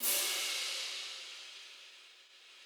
crash